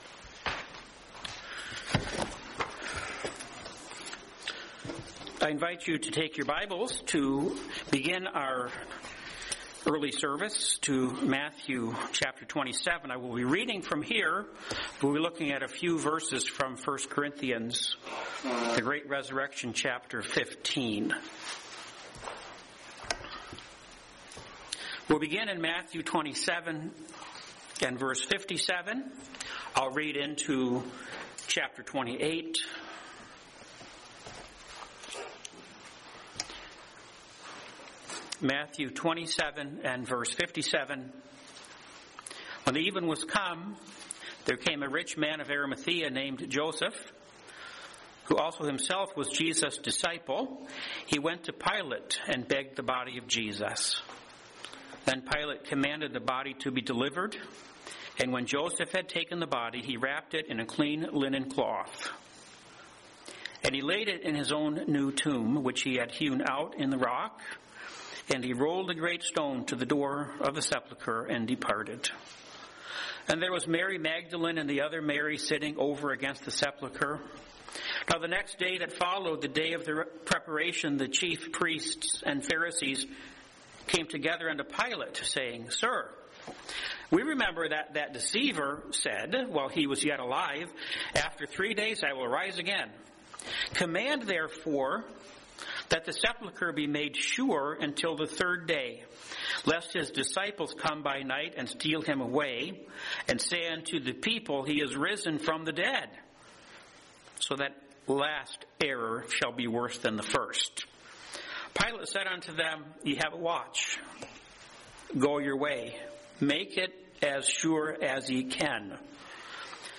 4/5/26 Easter Sunday Early Service Matthew 27:57-28:8